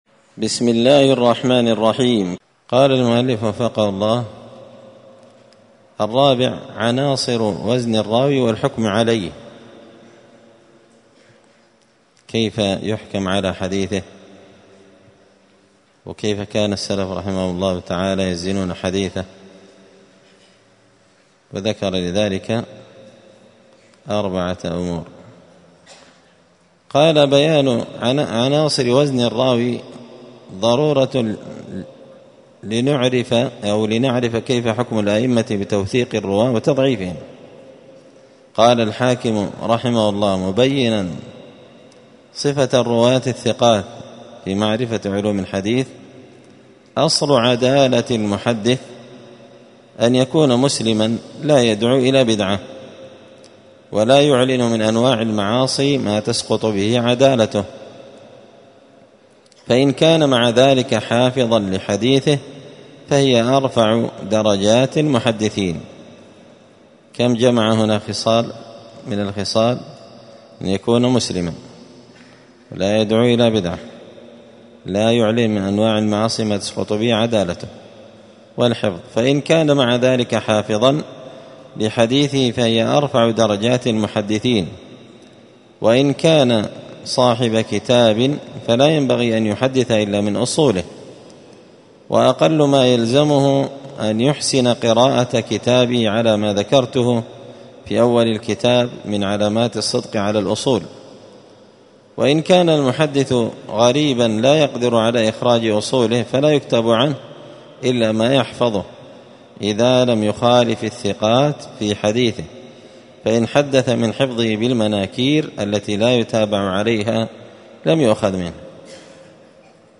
*الدرس الرابع (4) عناصر وزن الراوي والحكم عليه*